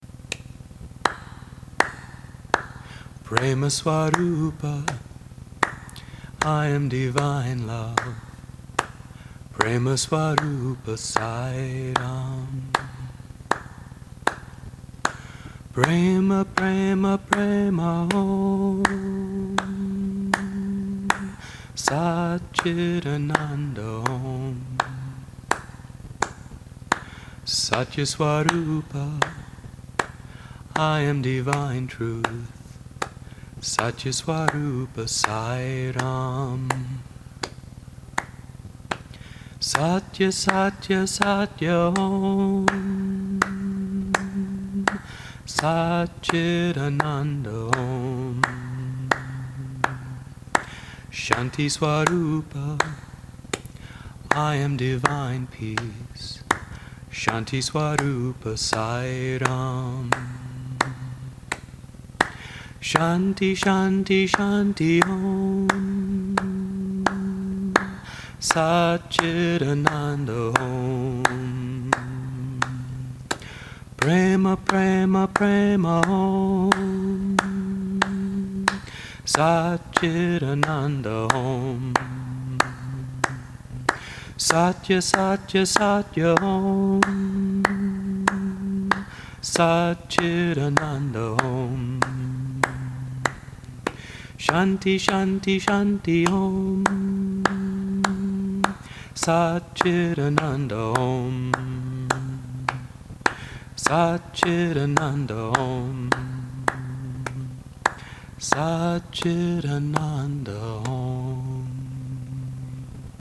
1. Devotional Songs
Minor (Natabhairavi)
6 Beat / Dadra
4 Pancham / F
1 Pancham / C